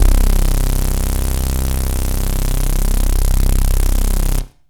SYNTH BASS-1 0019.wav